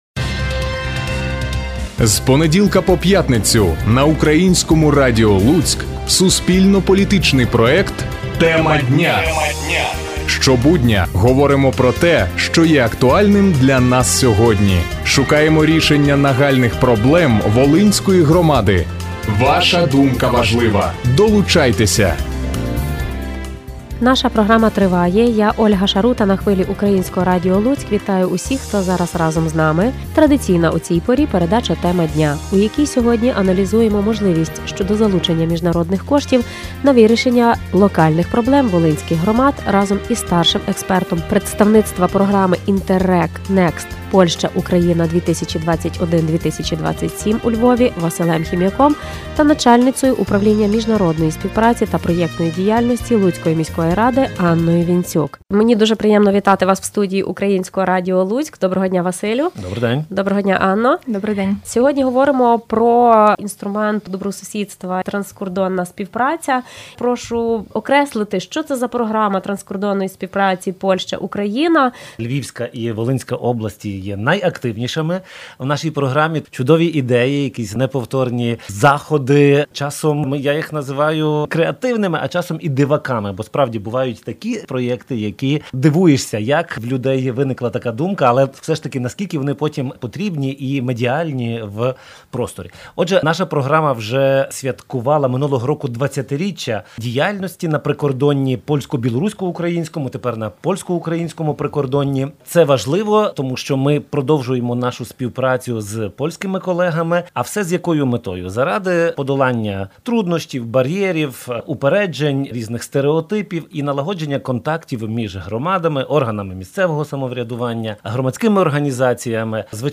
Castle, heroes, river, and wildlife – how the cross-border projects financed by our Programme are changing the city of Lutsk were the topic of a radio broadcast.